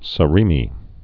(sə-rēmē, s-)